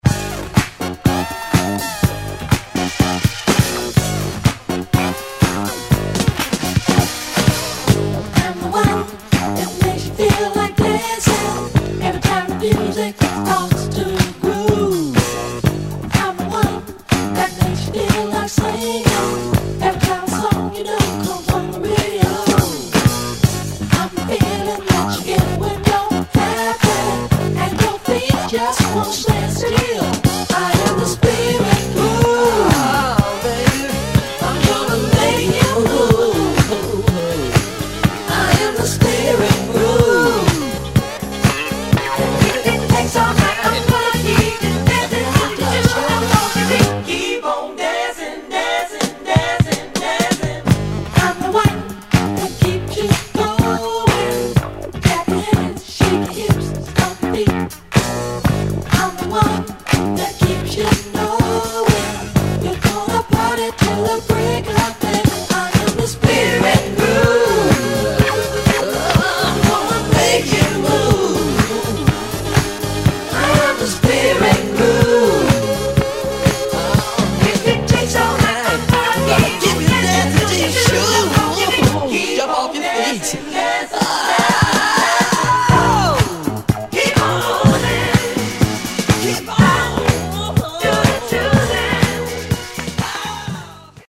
両面共に◎なSpacy Disco~Funk！